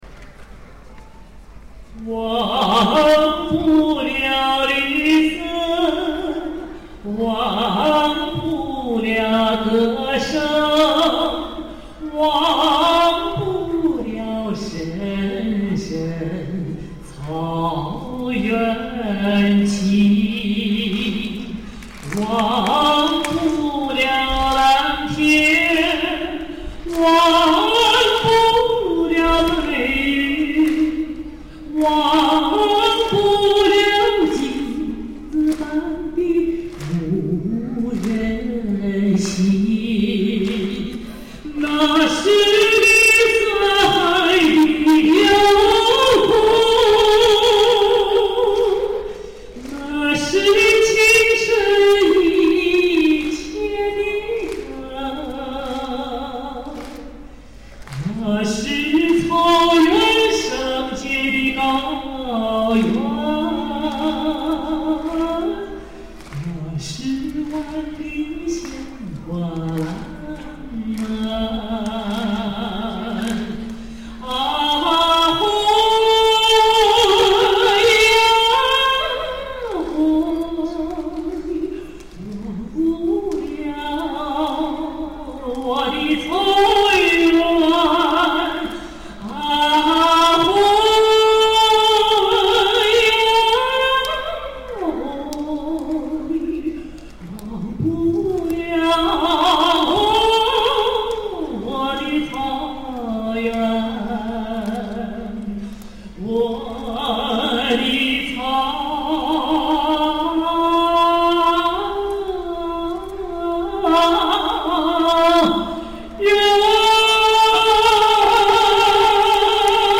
现场演唱
无伴奏独唱